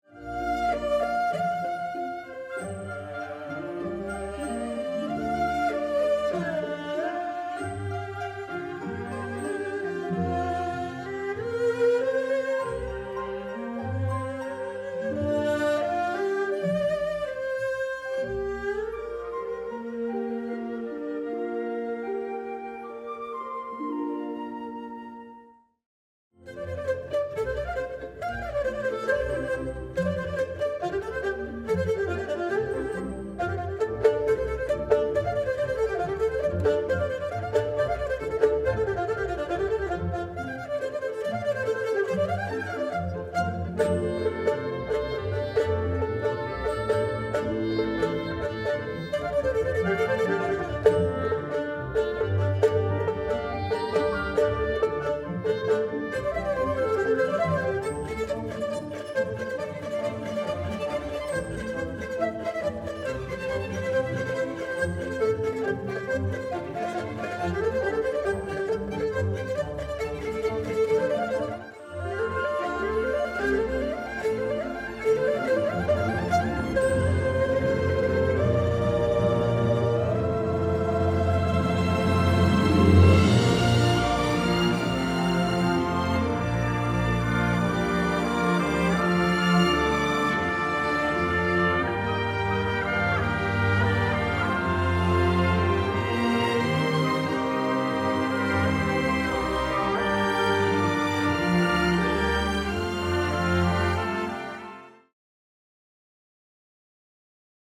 幅広く情緒を表現し、人の心を揺さぶる、数千年前に遡る古代楽器、二胡の奏曲。